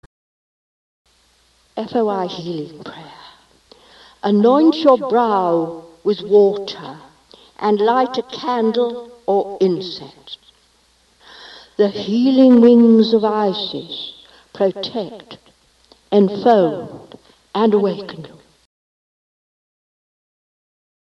FOI Healing Prayer: